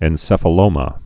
(ĕn-sĕfə-lōmə)